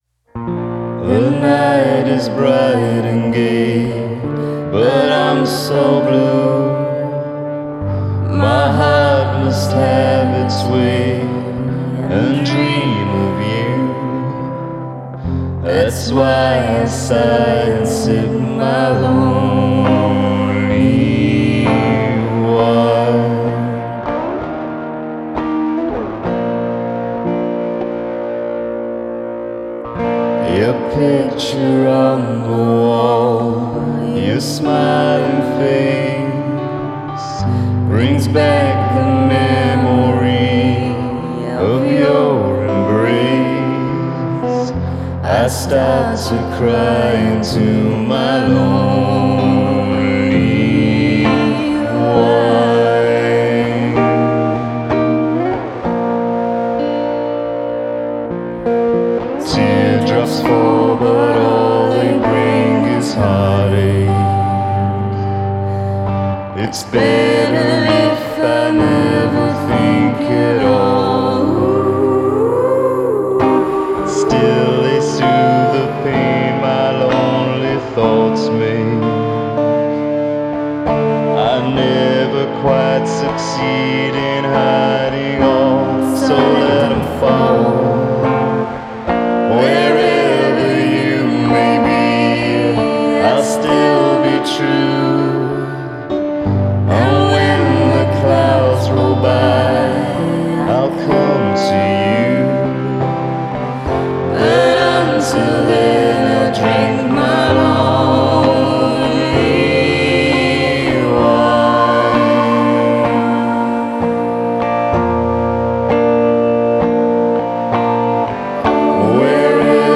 Жанр: Indie Pop
Style: Indie Pop